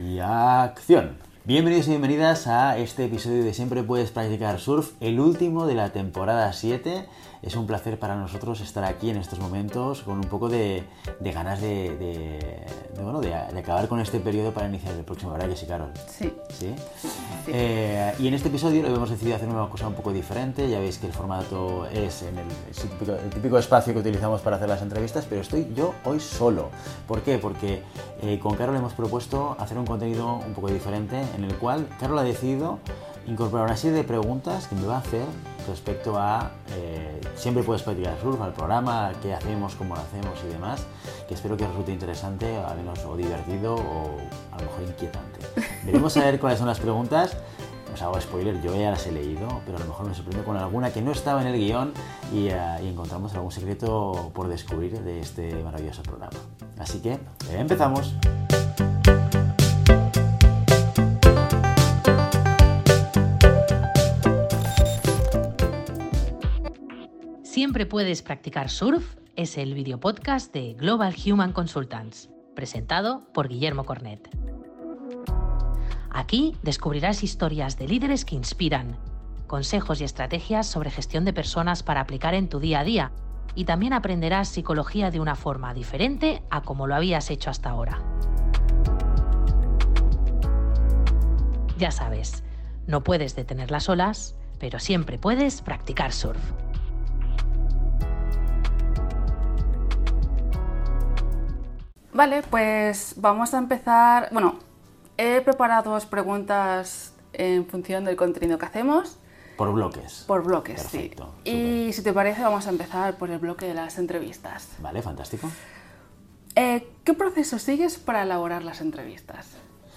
Cerramos la séptima temporada de Siempre Puedes Practicar Surf haciendo algo que nunca habíamos hecho antes: ¡darle la vuelta al micro!